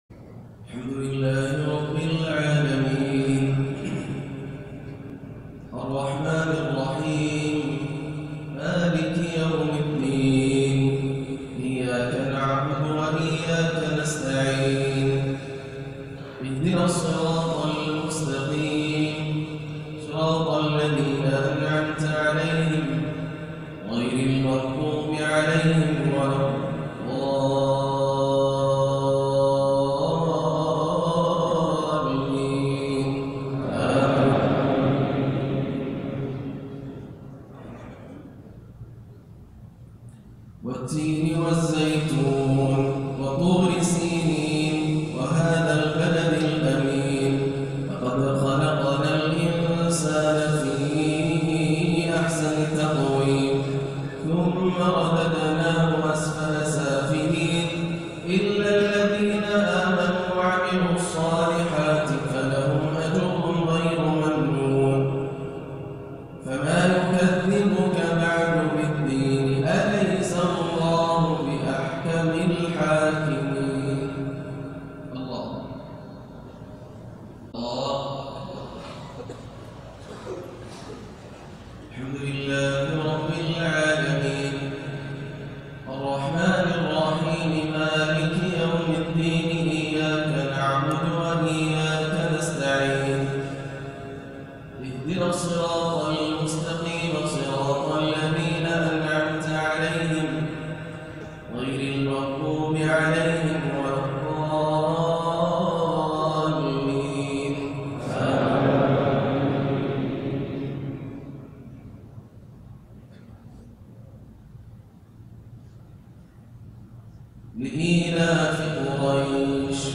صلاة الجمعة 9-1-1439هـ سورتي التين و قريش > عام 1439 > الفروض - تلاوات ياسر الدوسري